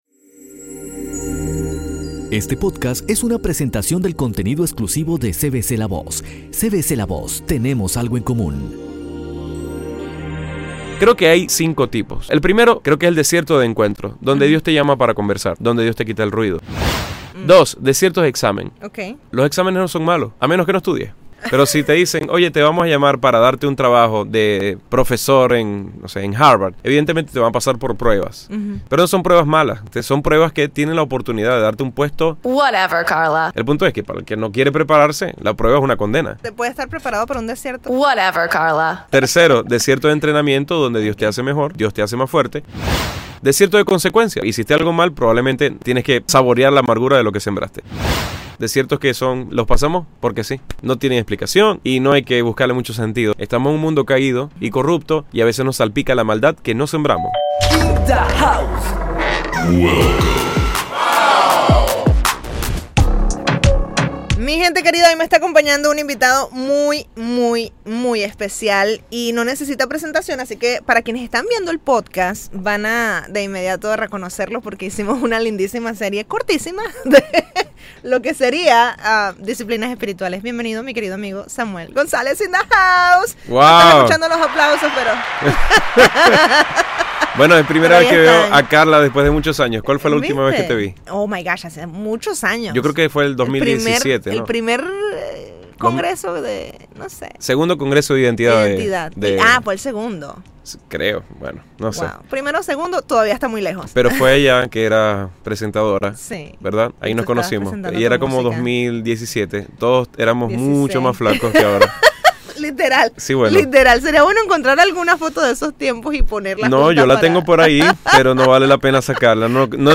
EN VIVO | al estudio para explicarnos este tema